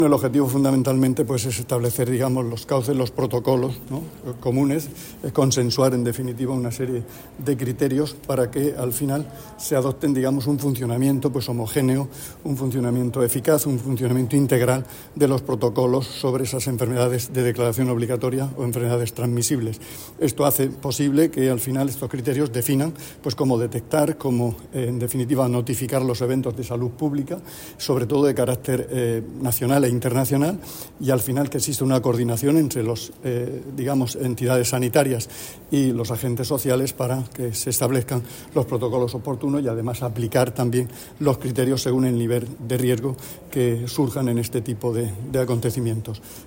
Declaraciones del consejero de Salud, Juan José Pedreño, sobre la reunión de la Ponencia de Vigilancia Epidemiológica celebrada en Murcia.